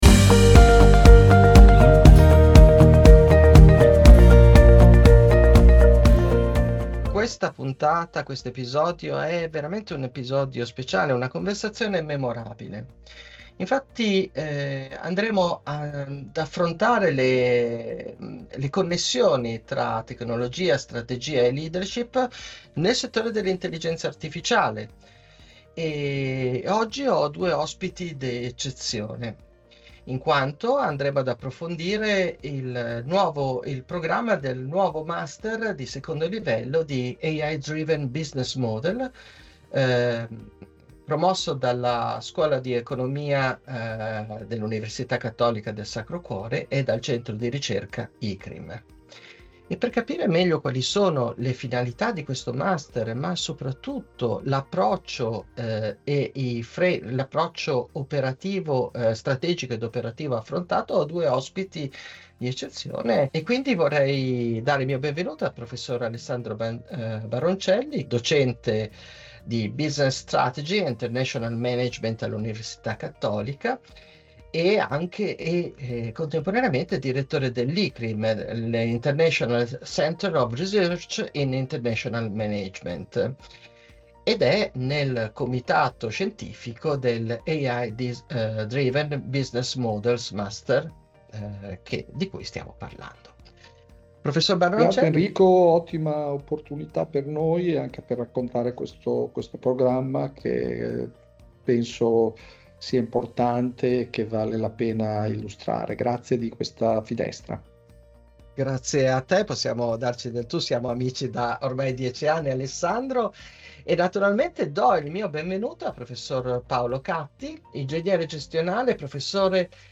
Ecco l’intervista dal vivo: